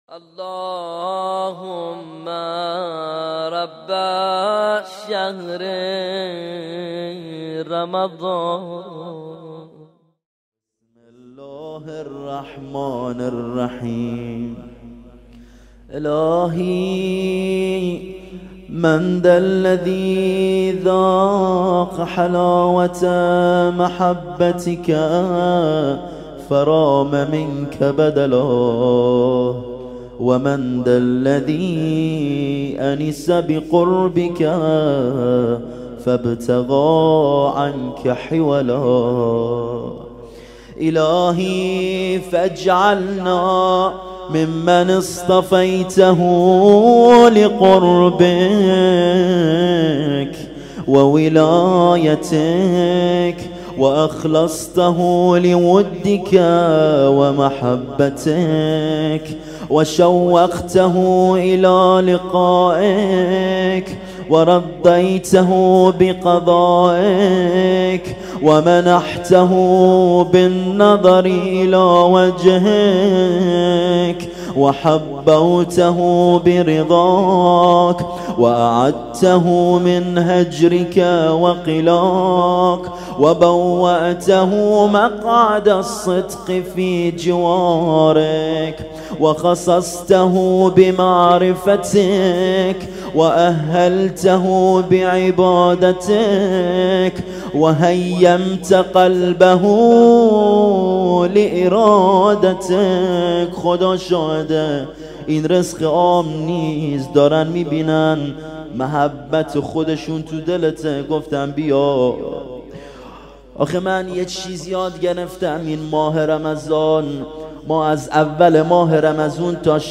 قرائت مناجات المحبین